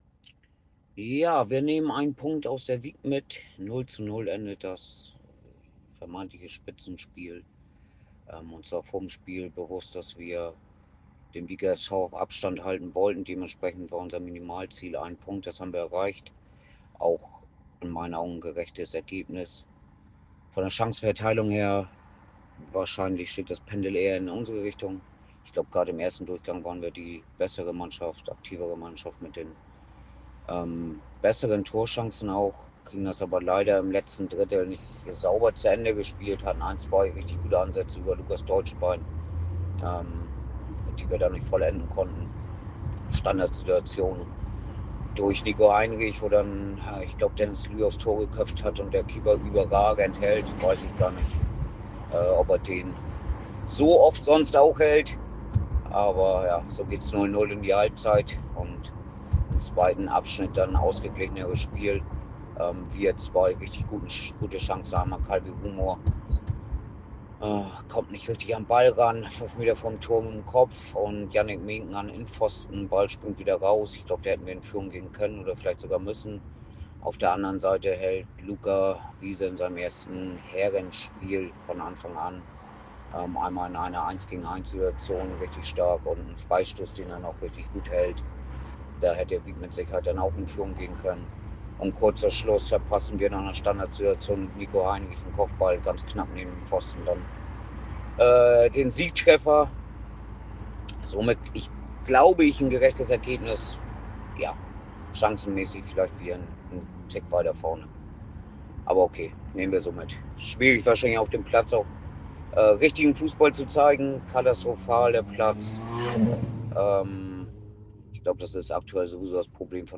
Stimme zum Spiel